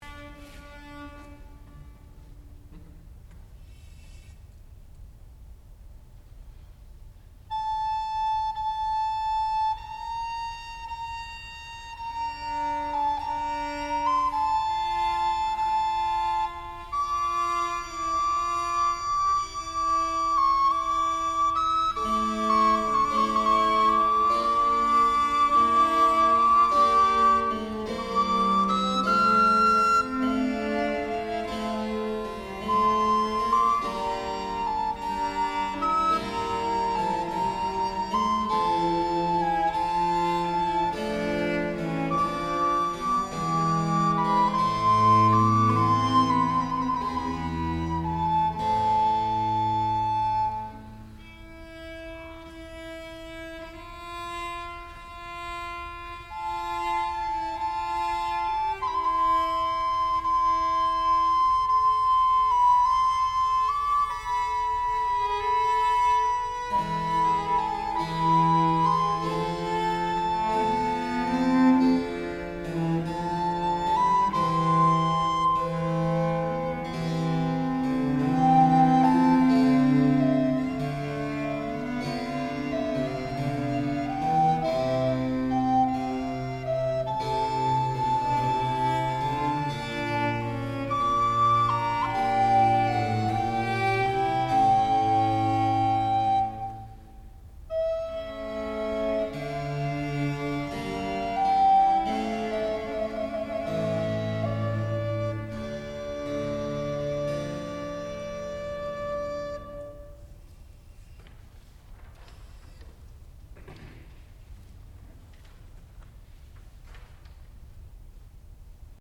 sound recording-musical
classical music
violoncello
harpsichord
viola da gamba
recorder